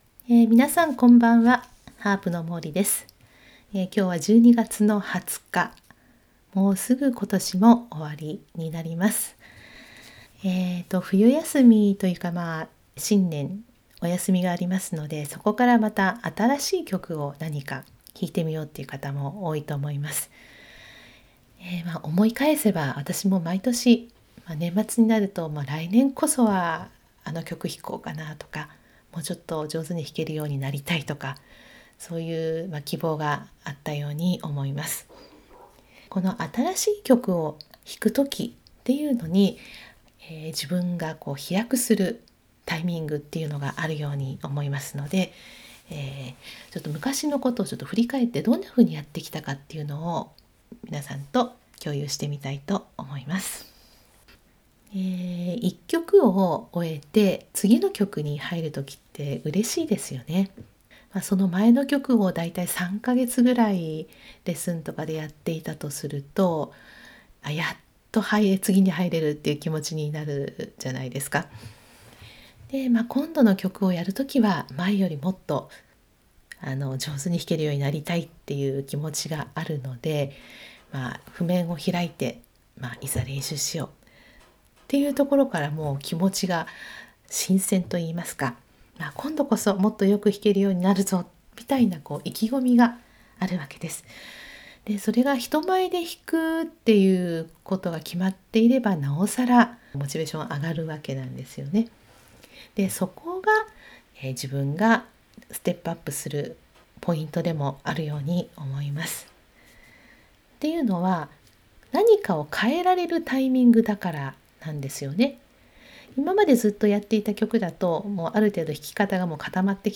（音声ブログ）新しい曲に入る時が自分が変われる時